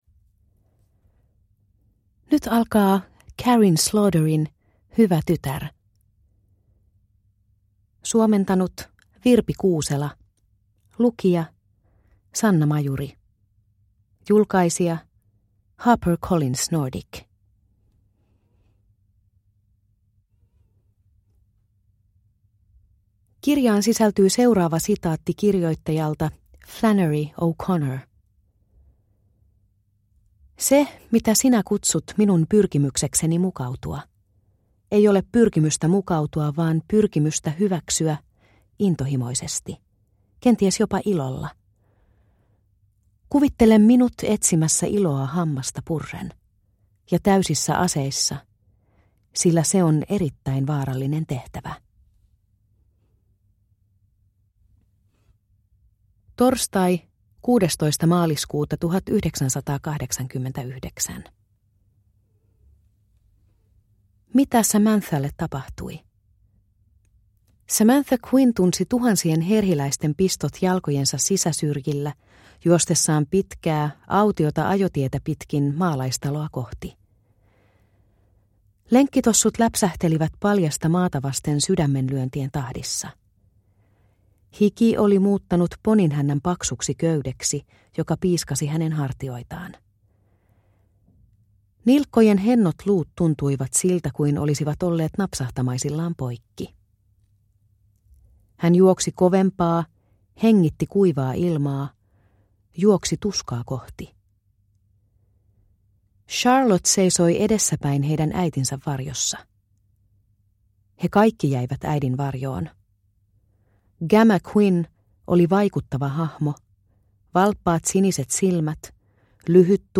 Hyvä tytär – Ljudbok – Laddas ner